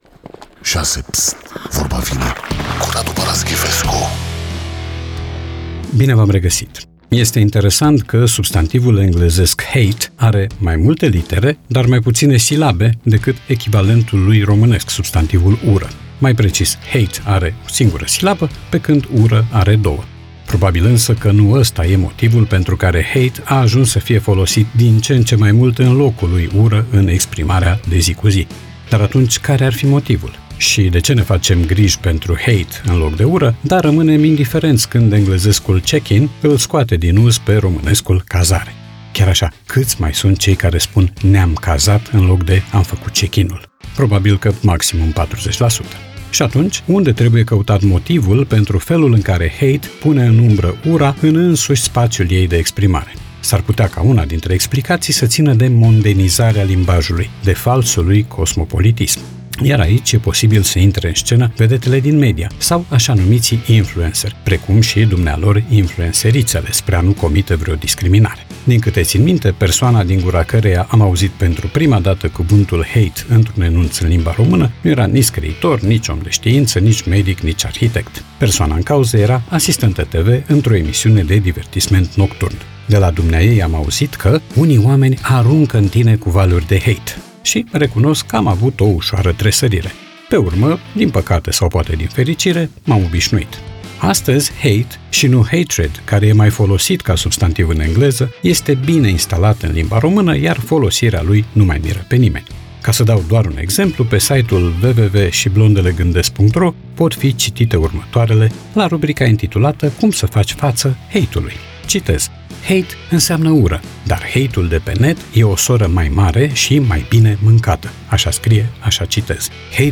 Podcast 1 august 2025 Vezi podcast Vorba vine, cu Radu Paraschivescu Radu Paraschivescu iti prezinta "Vorba vine", la Rock FM.